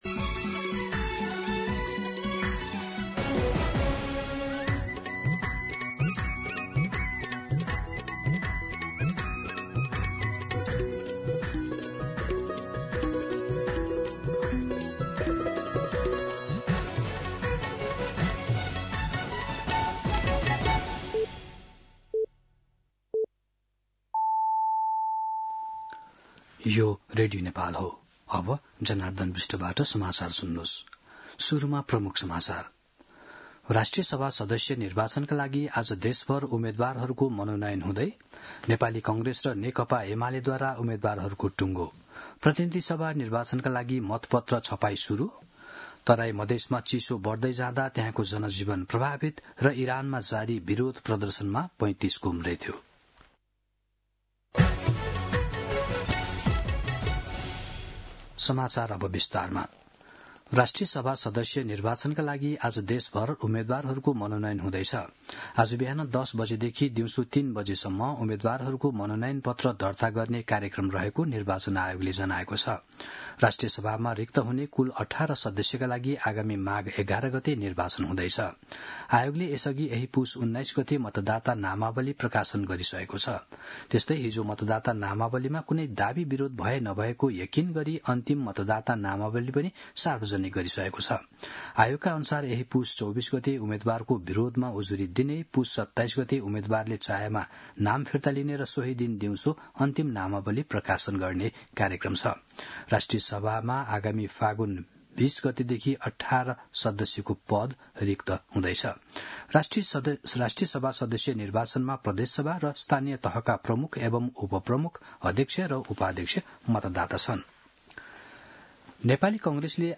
दिउँसो ३ बजेको नेपाली समाचार : २३ पुष , २०८२
3-pm-Nepali-News-1.mp3